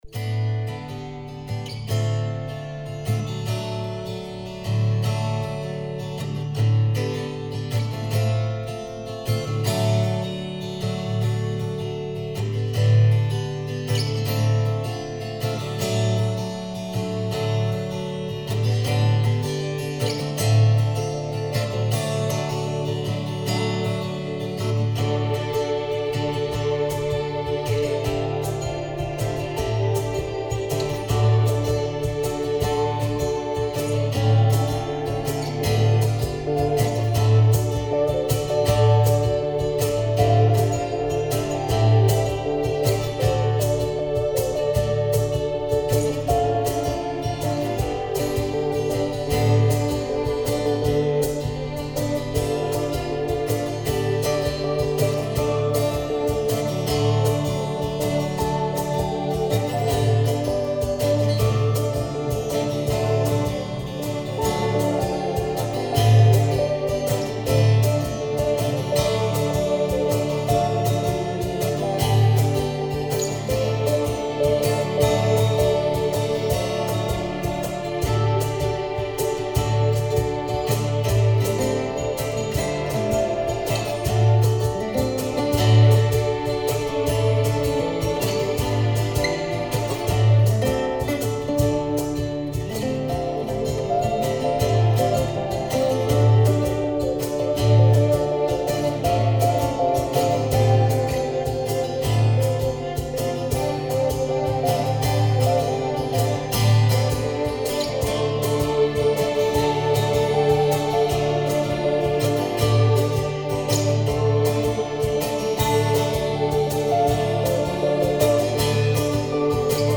Tempo: 80 bpm / Datum: 15.09.2016